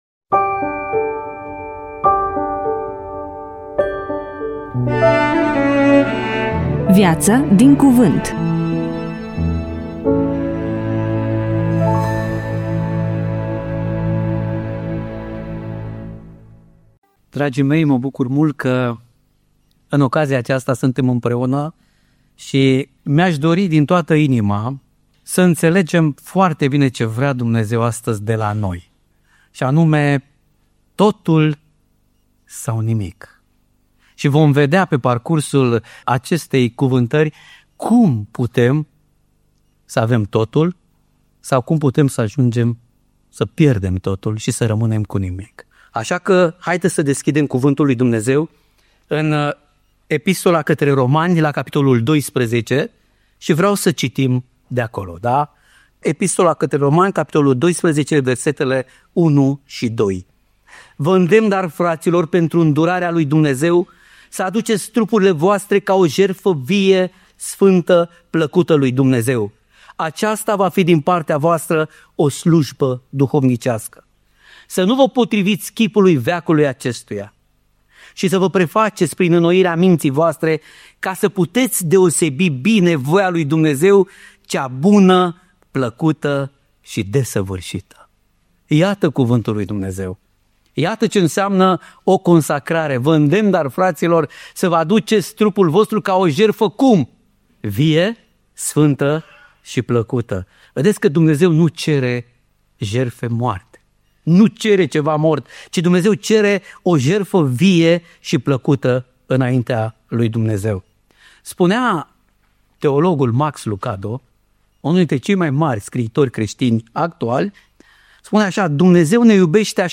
EMISIUNEA: Predică DATA INREGISTRARII: 17.01.2026 VIZUALIZARI: 16